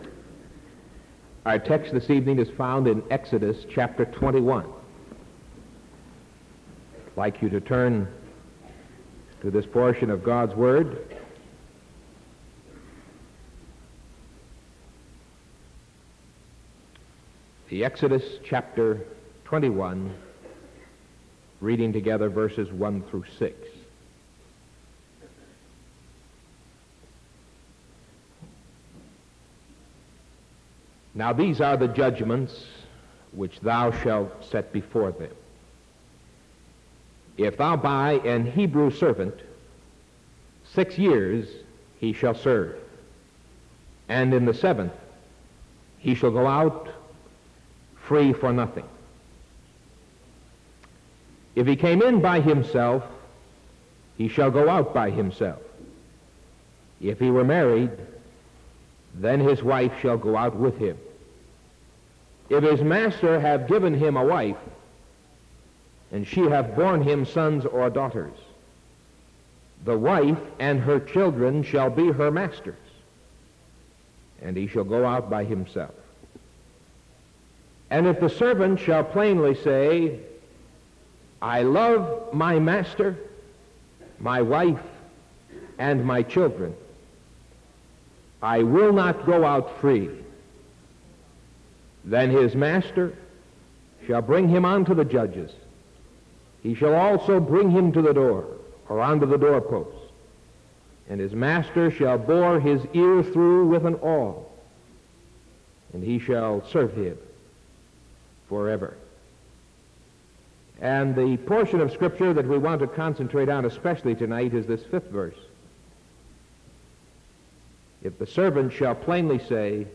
Sermon September 15th PM